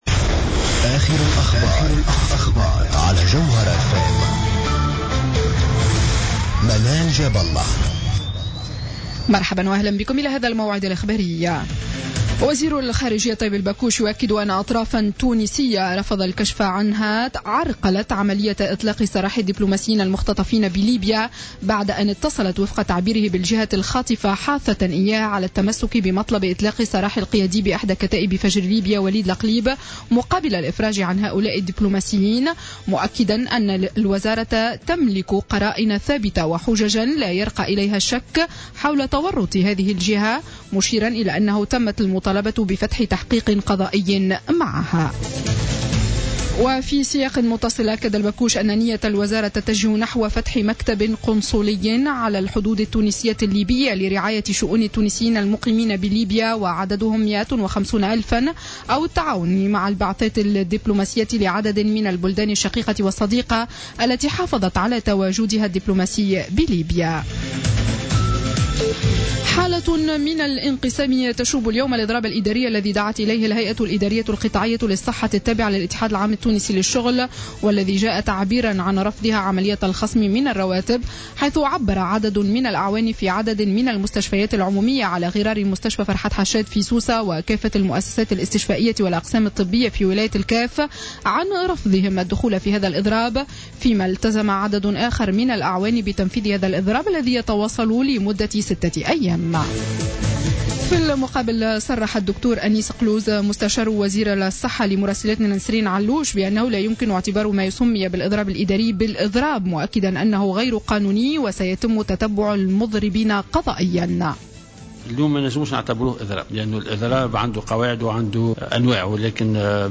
نشرة أخبار الساعة الخامسة مساء ليوم الاثنين 22 جوان 2015